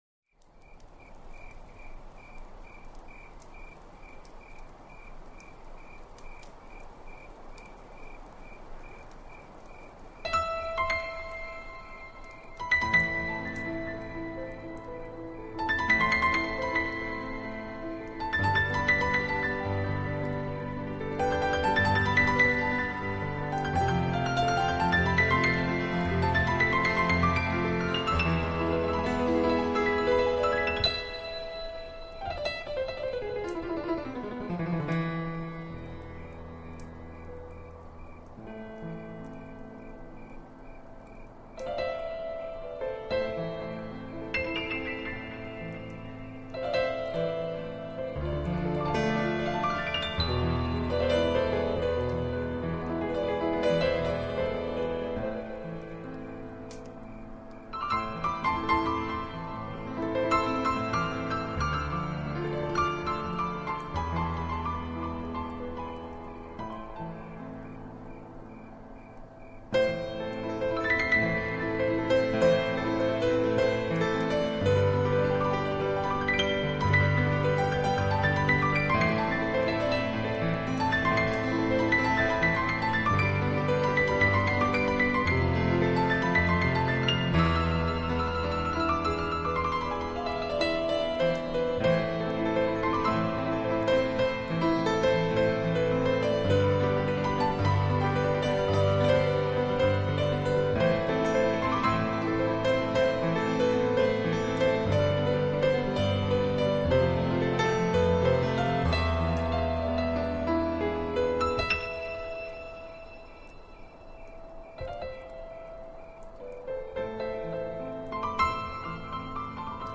涉及分类：新世纪音乐 New Age
涉及风格：减压音乐 Relaxation、器乐 Instrumenta 、自然音乐 Nature
使用乐器：钢琴 Pi